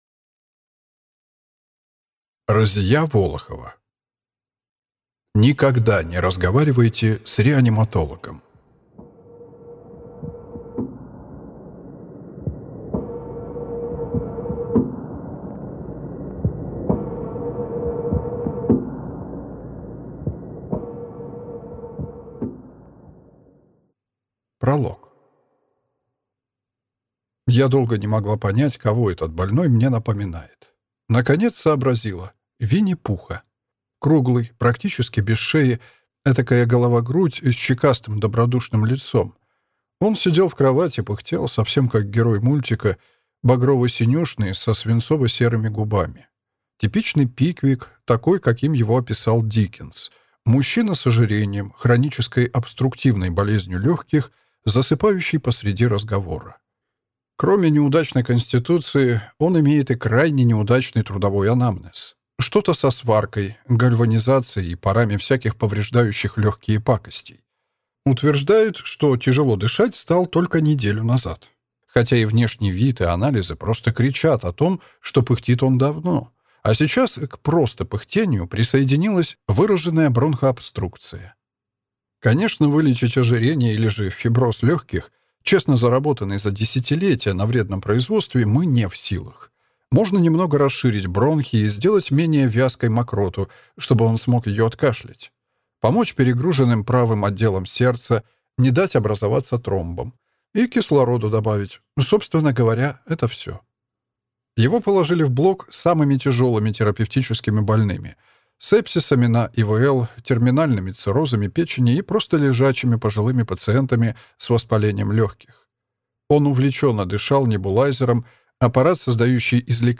Аудиокнига Никогда не разговаривайте с реаниматологом | Библиотека аудиокниг